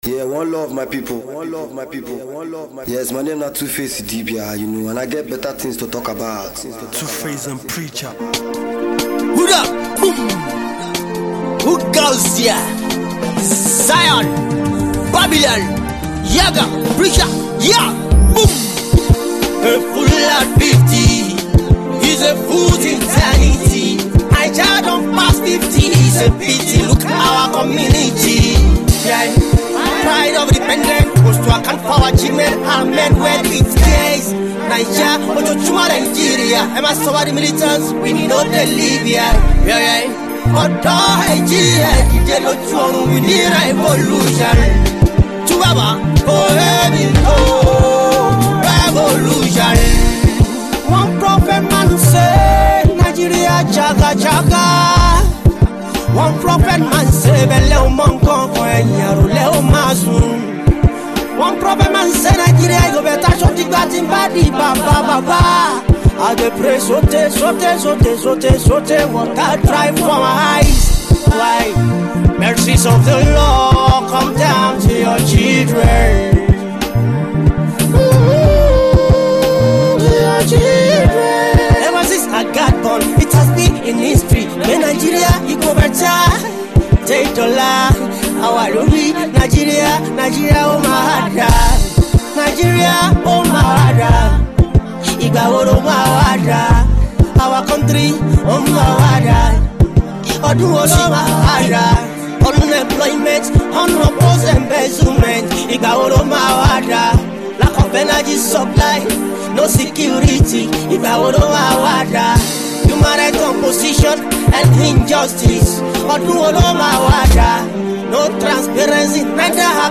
powerful vocals